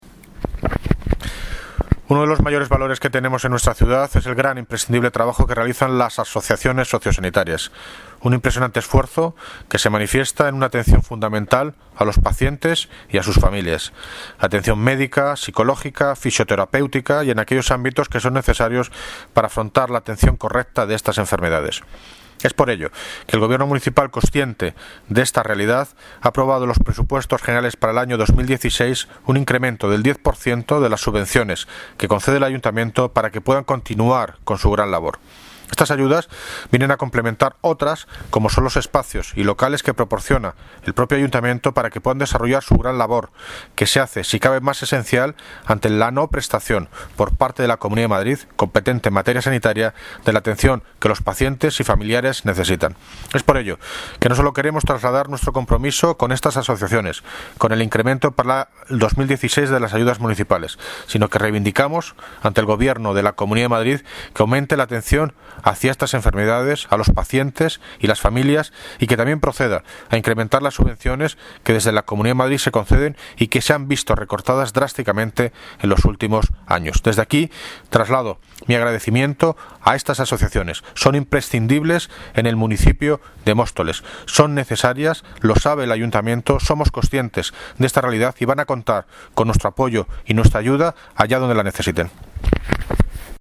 Audio - David Lucas (Alcalde de Móstoles) sobre incremento de ayudas a asociaciones sociosanitarias